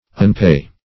Search Result for " unpay" : The Collaborative International Dictionary of English v.0.48: Unpay \Un*pay"\, v. t. [1st pref. un- + pay.]
unpay.mp3